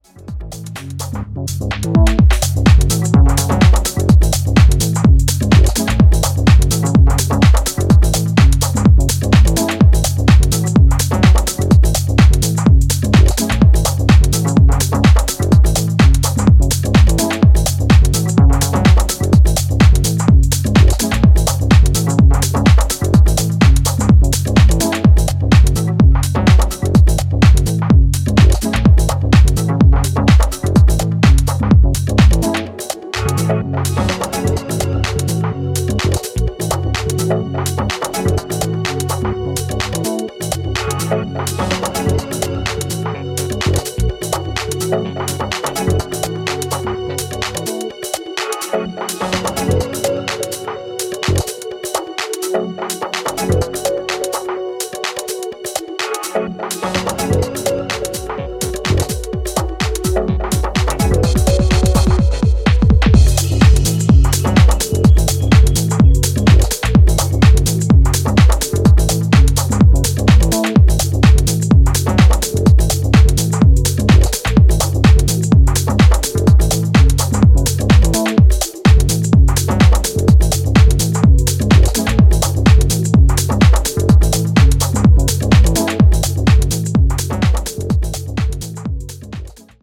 ジャンル(スタイル) TECH HOUSE / DEEP HOUSE